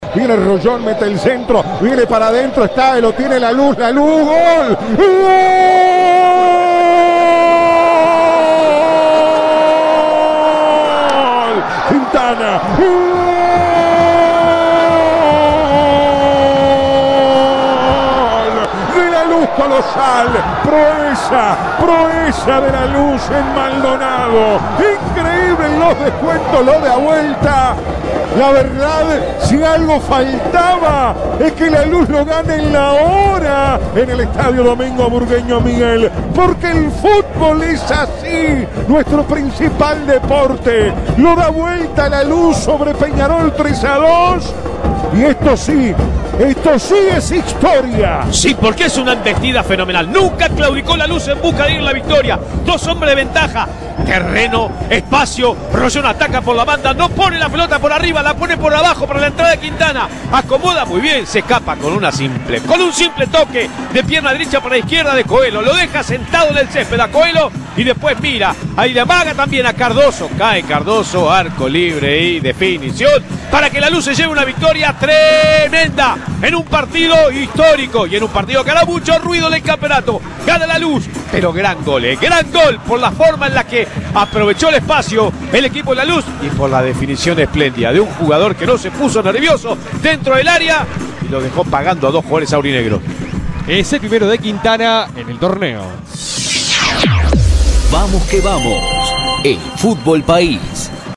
El partido de locos entre merengues y carboneros en ma voz del equipo de VQV